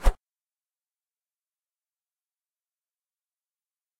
walljump.ogg